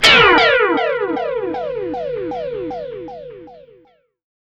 GUITARFX 7-R.wav